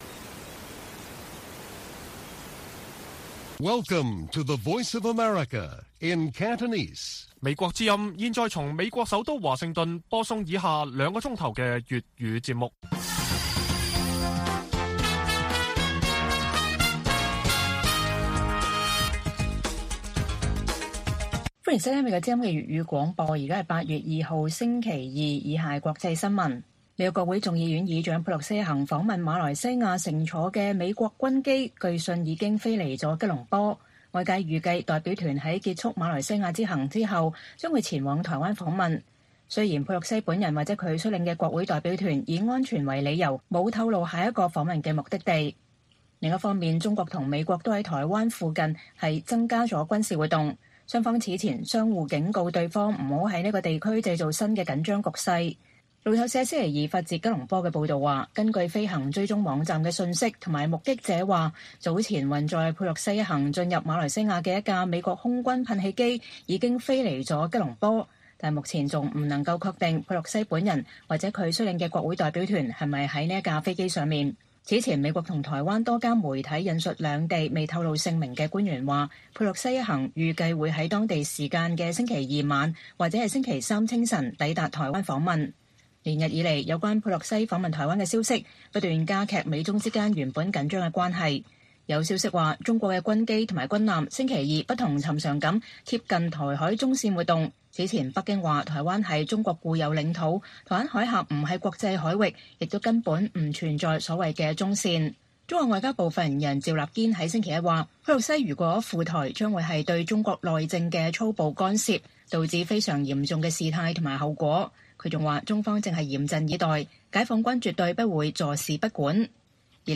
粵語新聞 晚上9-10點: 佩洛西乘坐美國軍機飛離吉隆坡，據信下一站是台北